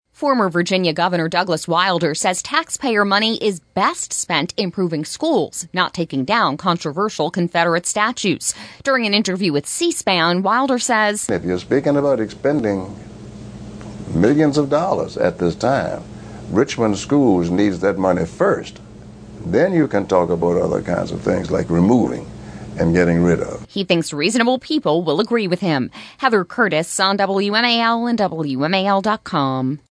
Former Virginia Gov. Douglas Wilder said during an interview on C-SPAN Thursday that his election in 1989 showed how Virginia had changed.